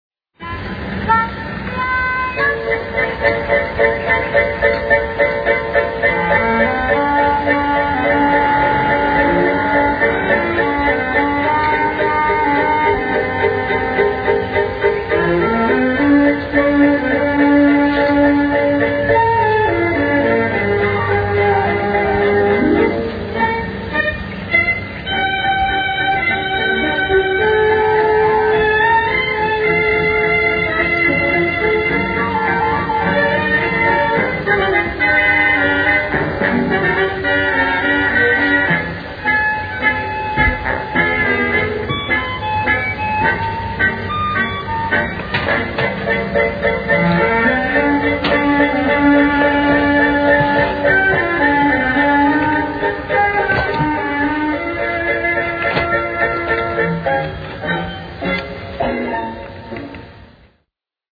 instrumental opening theme